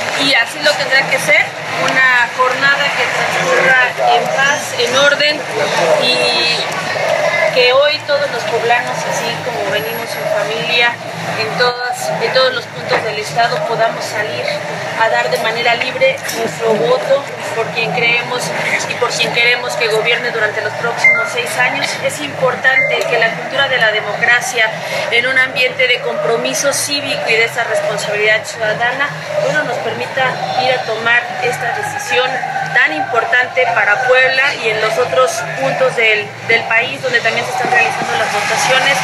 En breve entrevista posterior a emitir su sufragio en el barrio de San Miguelito, Rivera Vivanco abundó que durante la mañana de este domingo todo transcurría con normalidad y con orden en la capital de Puebla donde no se percibió alguna situación que pudiera generar alguna alerta.